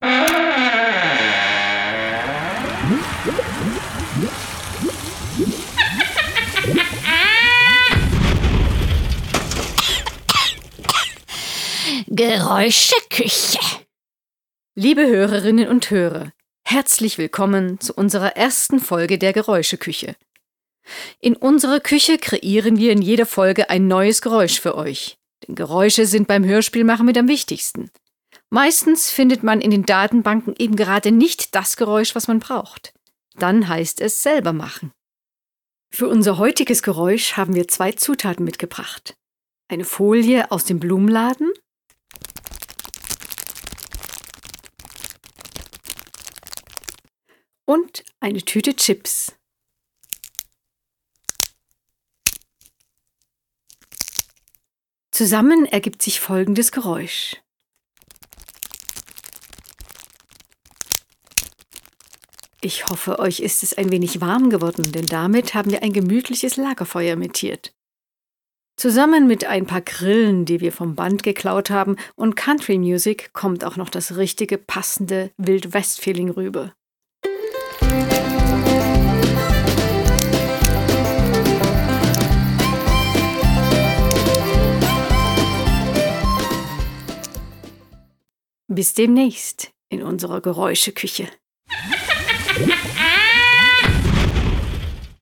Die Geräuscheküche stellt in jeder Folge ein neues Geräusch vor. Vom Meeresrauschen bis zur Mondrakete ist alles dabei und es wird natürlich auch verraten, wie man diese Geräusche selber herstellen kann.
geraeuschekueche-folge-1-lagerfeuer.mp3